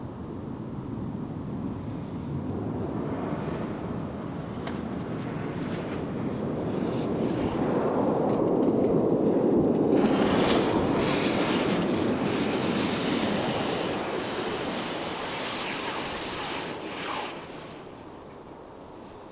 airsound2.mp3